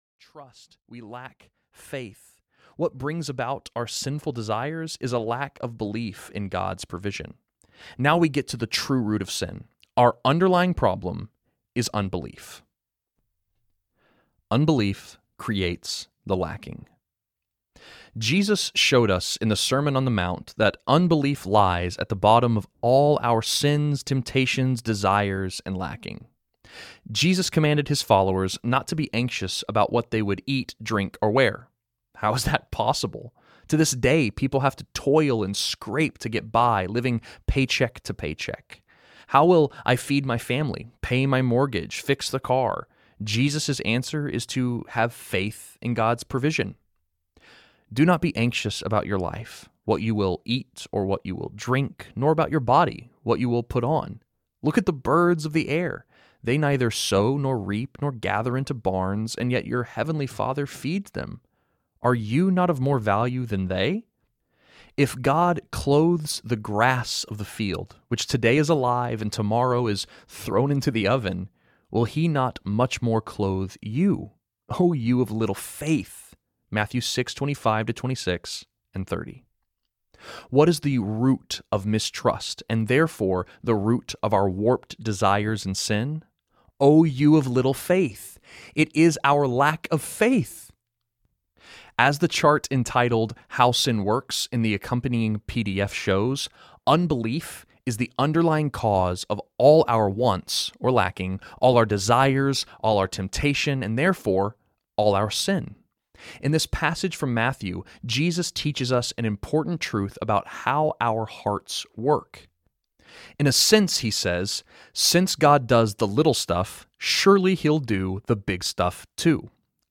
Rewire Your Heart Audiobook
7.75 Hrs. – Unabridged